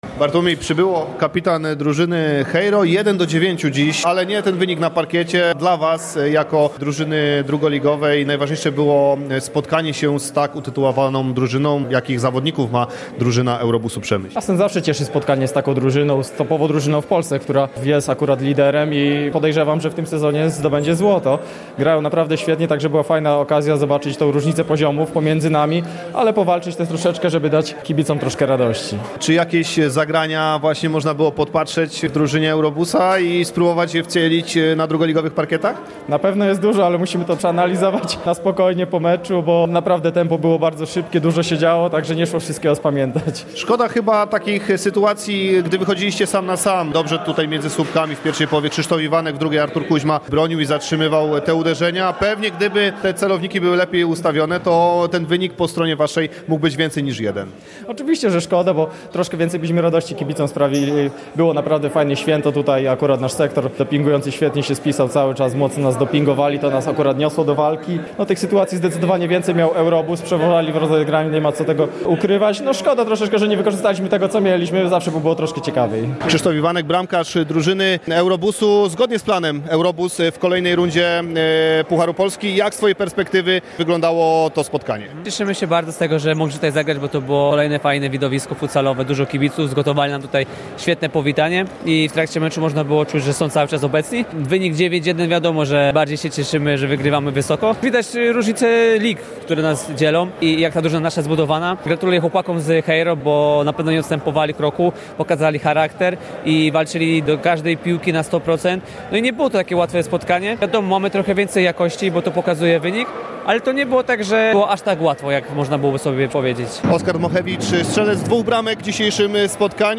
Pomeczowe rozmowy